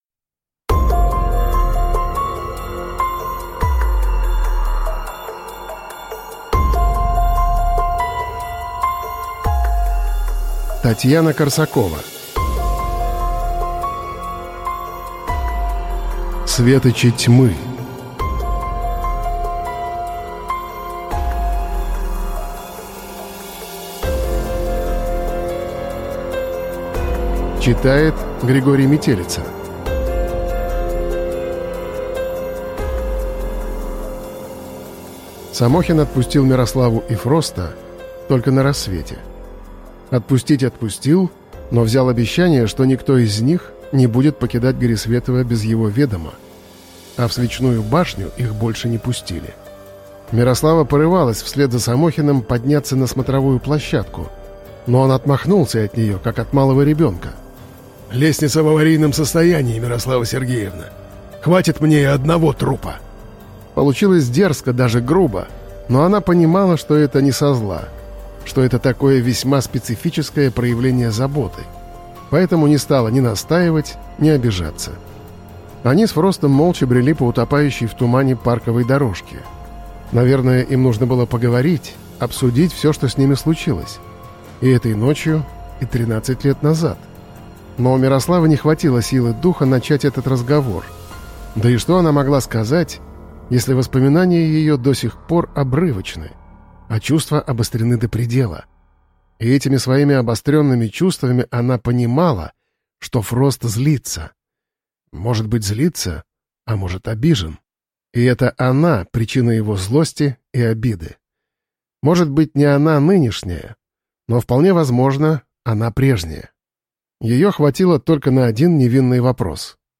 Аудиокнига Светочи Тьмы | Библиотека аудиокниг